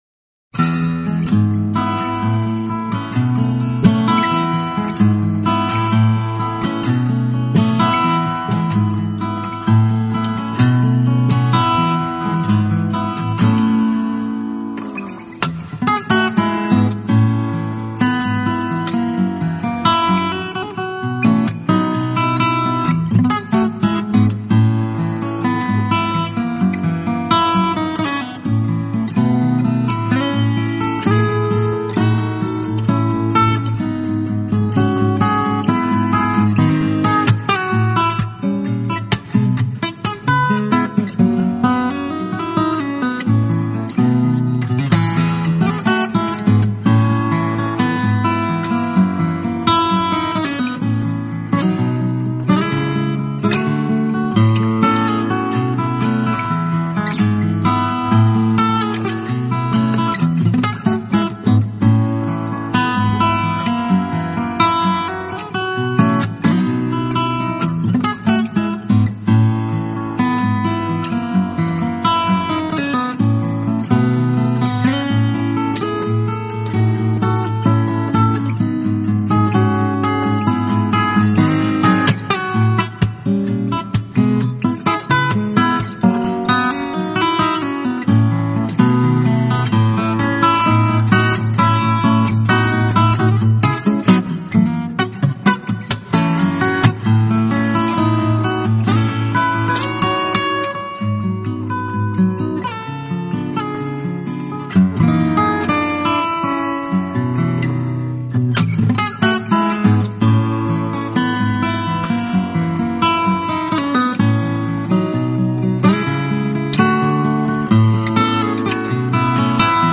【指弹吉他】
这张专辑录制于1999年，这也是他的第一张纯音乐独奏专辑。